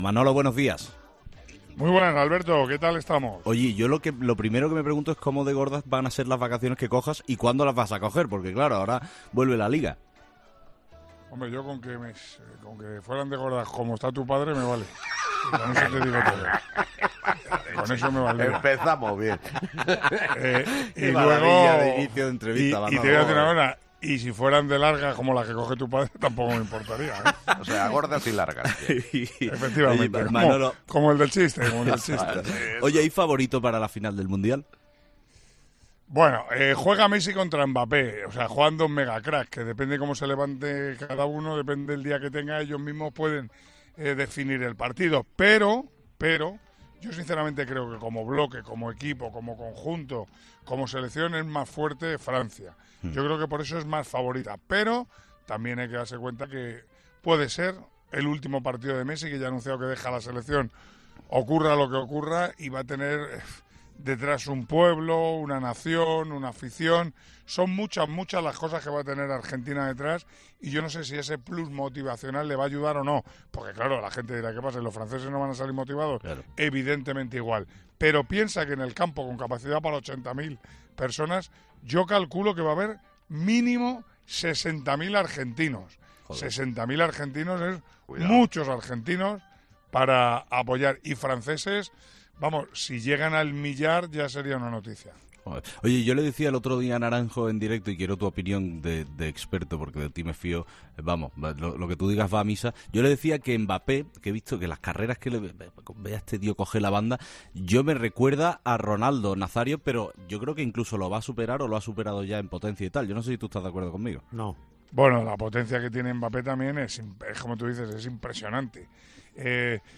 "Ay mi madre", es lo primero que ha dicho Manolo Lama cuando le han preguntado en 'Herrera en COPE' por el futuro de Cristiano Ronaldo.
Escucha el audio en el que Manolo Lama da su opinión sobre el tema: